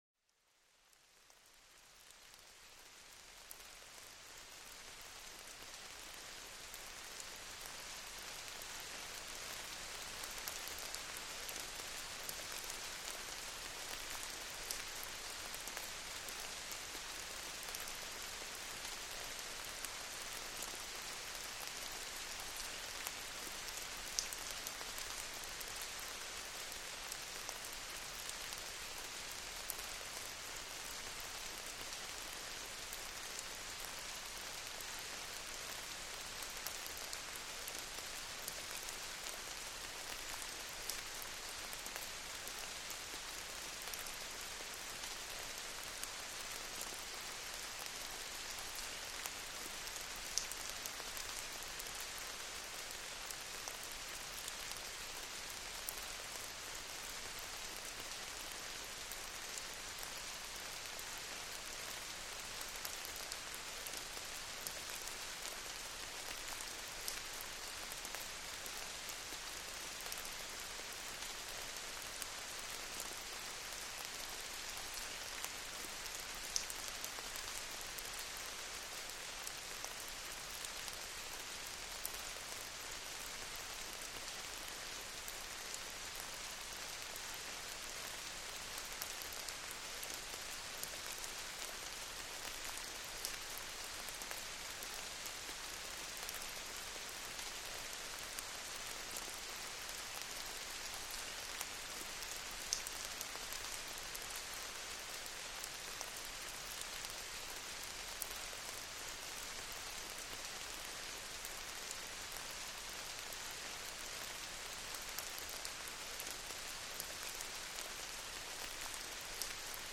Lluvia Suave para una Relajación Perfecta
Cierra los ojos e imagina estar bajo un cielo nublado, mecido por el ritmo delicado de cada gota.